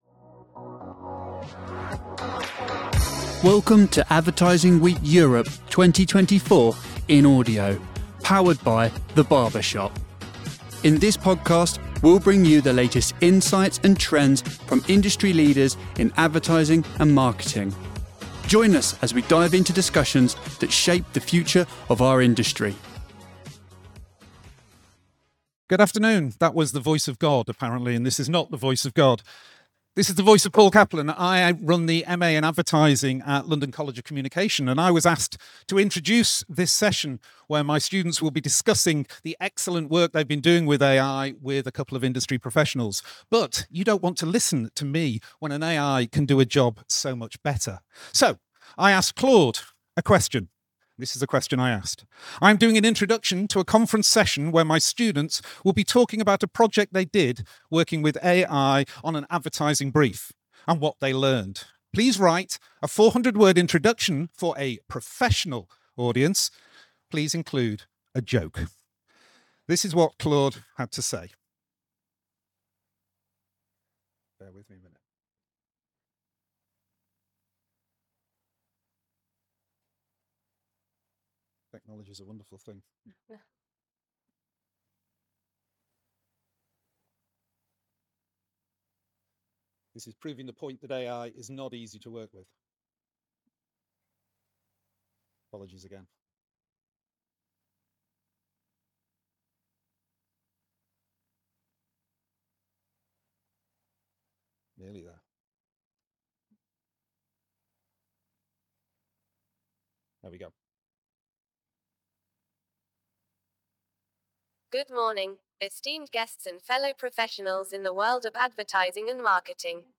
A panel of experts discusses the future of AI in advertising, exploring practical applications, successes, and challenges of AI-driven projects.